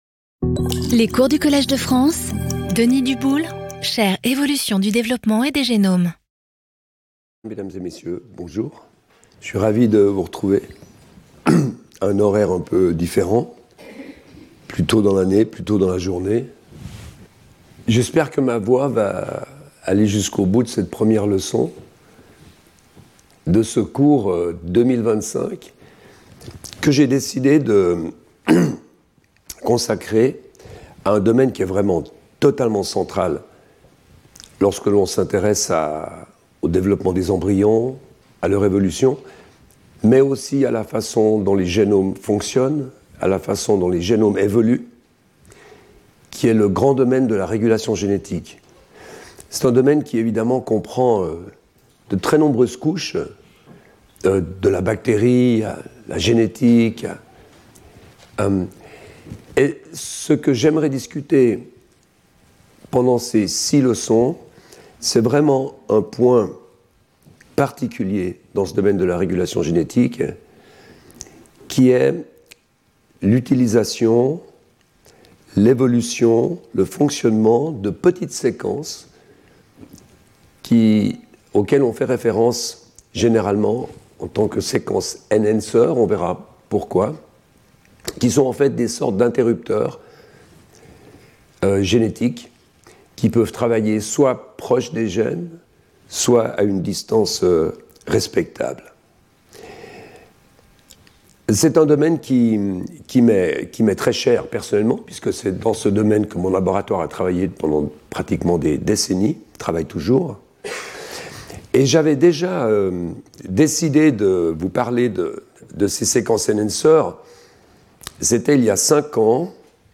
Intervenant(s) Denis Duboule Professeur du Collège de France